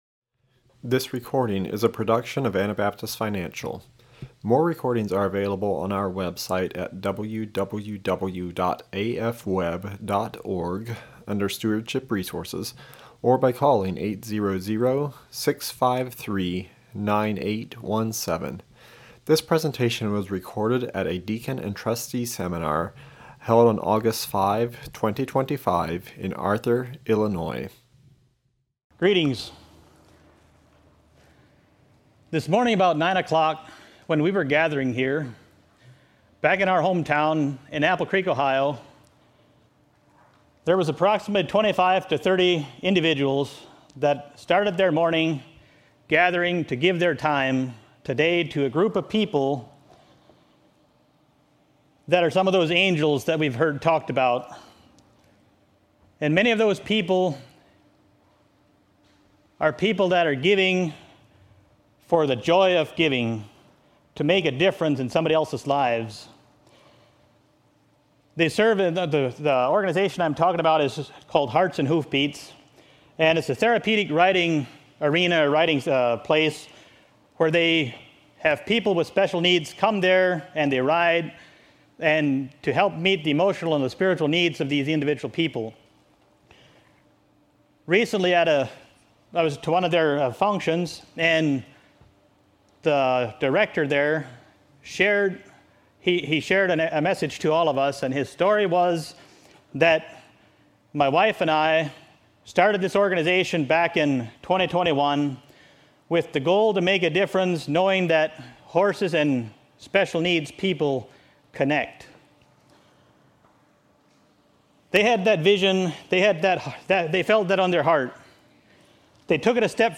This is a recording from the Seminar for Deacons, Financial Advisors, and Trustees held in Arthur, IL in 2025.